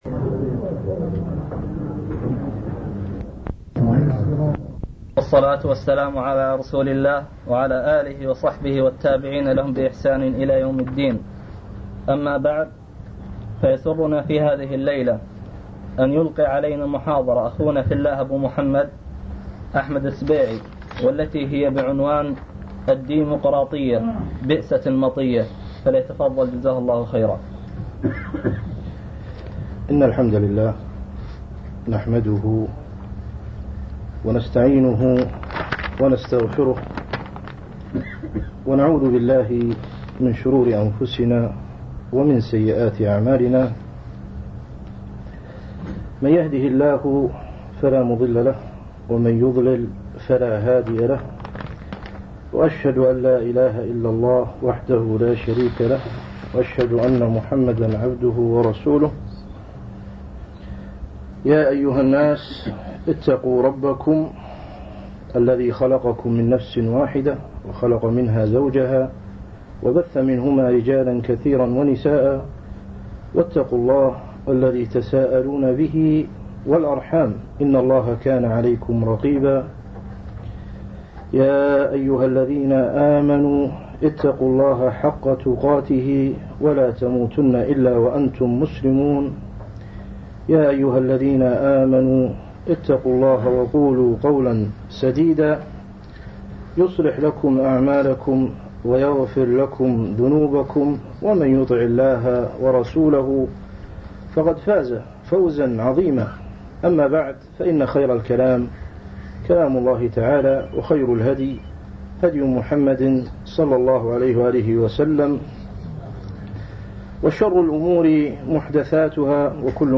Format: MP3 Mono 22kHz 32Kbps (CBR)